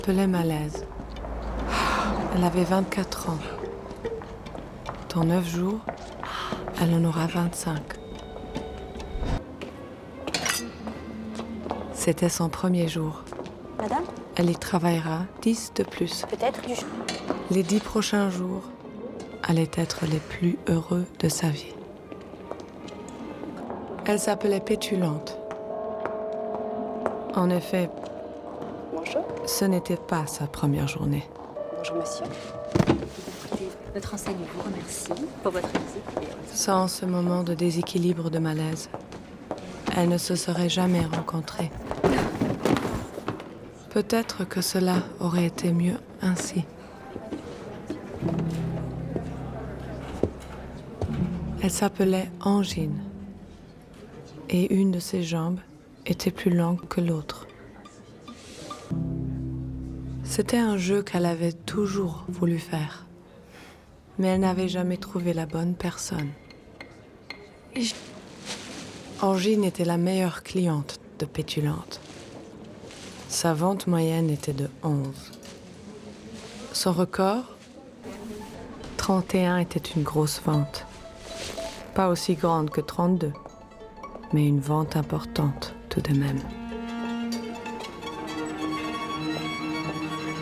Accent:: French
Gender:: Female Style:: Assured Gravitas Husky Rich Soft Storytelling
VICKY KRIEPS - TWO PEOPLE EXCHANGING SALIVA - SHORT FILM NARRATION.mp3